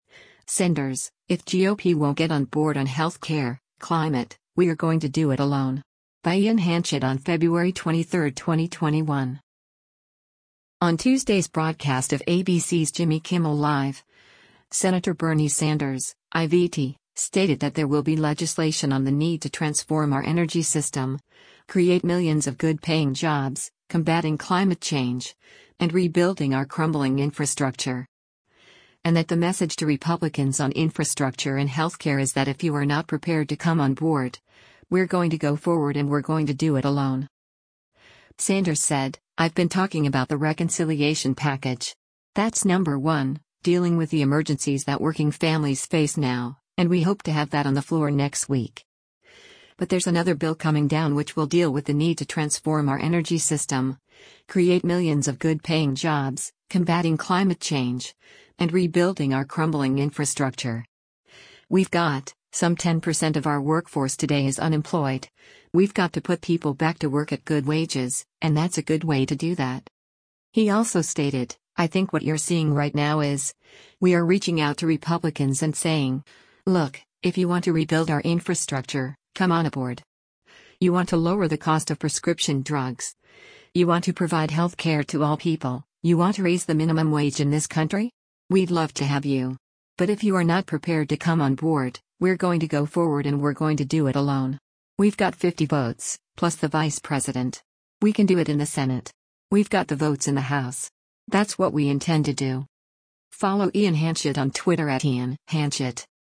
On Tuesday’s broadcast of ABC’s “Jimmy Kimmel Live,” Sen. Bernie Sanders (I-VT) stated that there will be legislation on “the need to transform our energy system, create millions of good-paying jobs, combating climate change, and rebuilding our crumbling infrastructure.” And that the message to Republicans on infrastructure and health care is that “if you are not prepared to come on board, we’re going to go forward and we’re going to do it alone.”